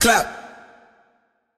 TS Vox_17.wav